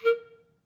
Clarinet
DCClar_stac_A#3_v2_rr1_sum.wav